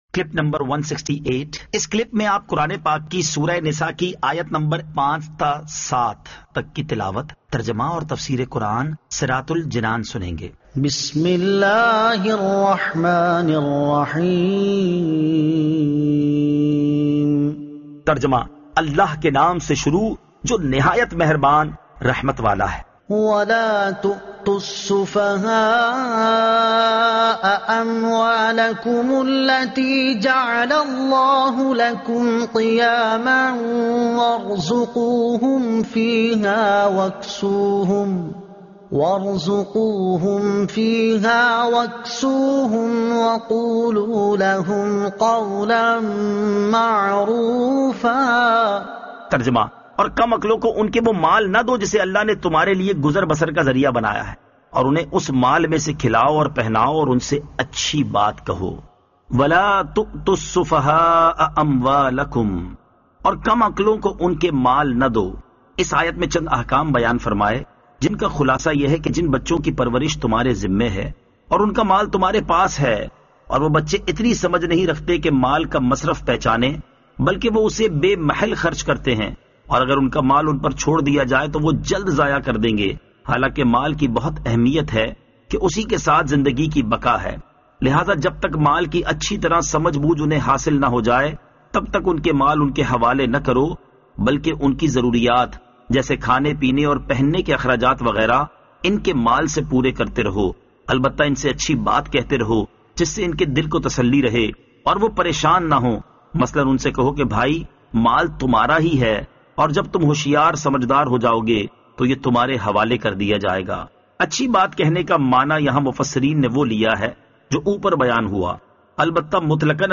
Surah An-Nisa Ayat 05 To 07 Tilawat , Tarjuma , Tafseer